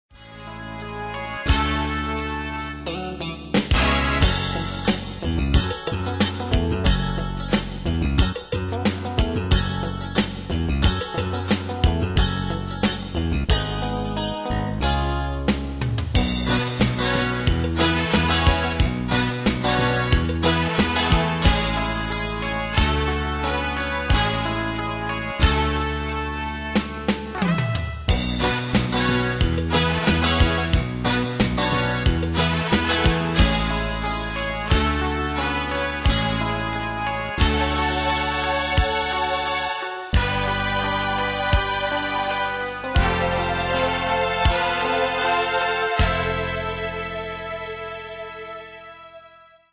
Bandes et Trames Sonores Professionnelles
Pro Backing Tracks